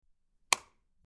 Light Switch Click
Light_switch_click.mp3